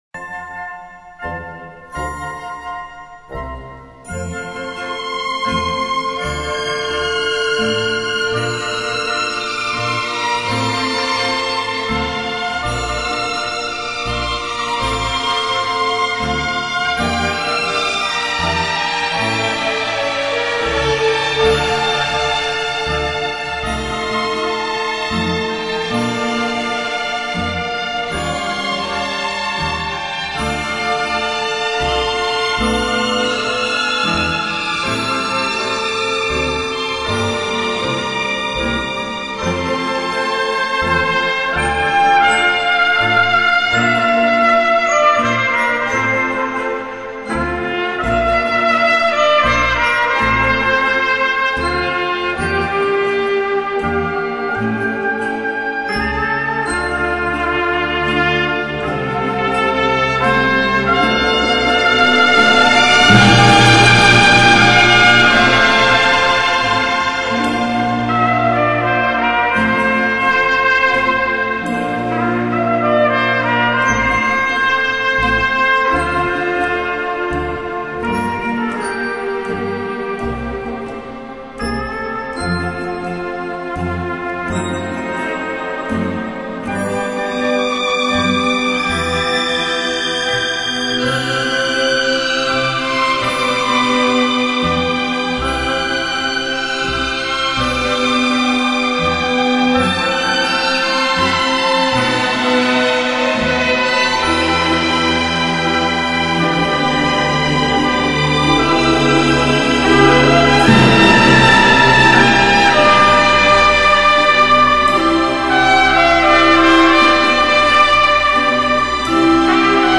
在这里，他巧妙地依靠管弦乐队中的弦乐器，找到了一种富有特殊色彩的音响，这种富有特色的弦乐演奏，使他的音乐流传世界各地。